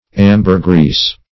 \Am"ber*grease\ ([a^]m"b[~e]r*gr[=e]s)